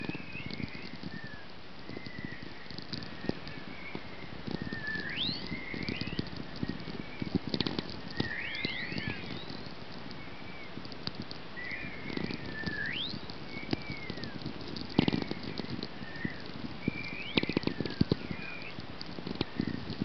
Звук северного сияния